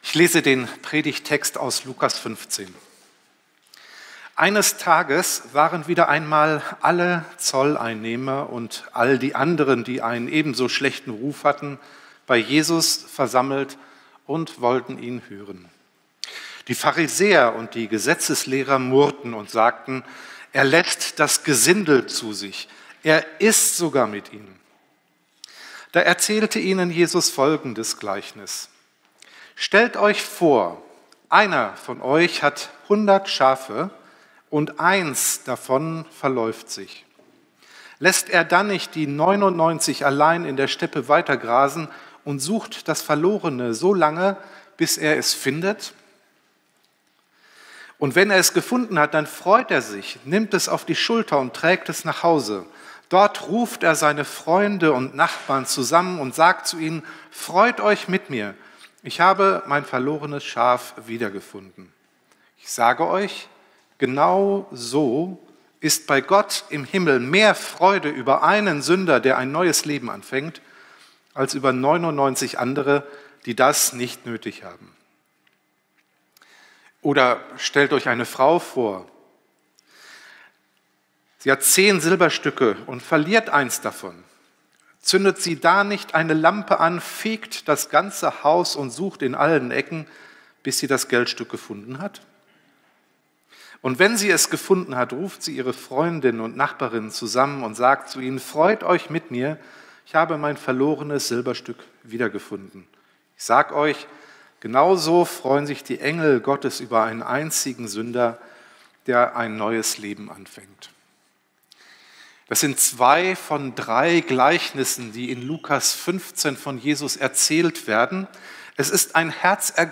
04-Predigt-6.mp3